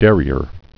(dârē-ər)